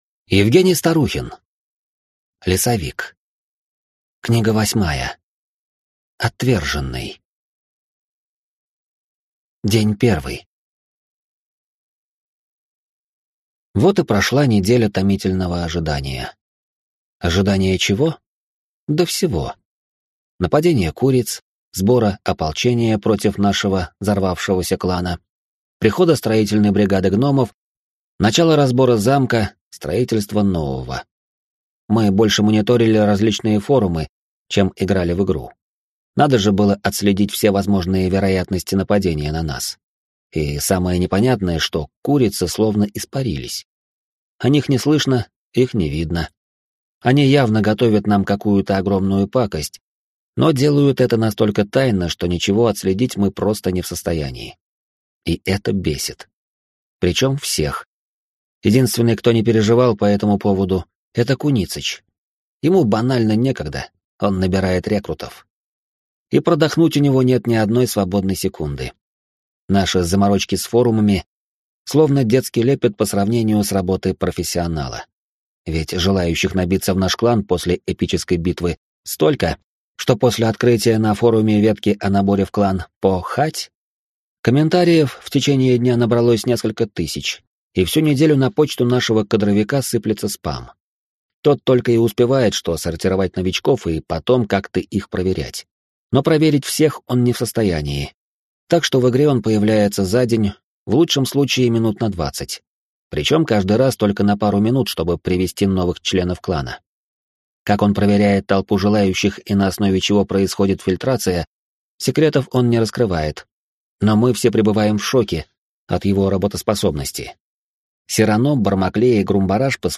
Аудиокнига Лесовик. Отверженный | Библиотека аудиокниг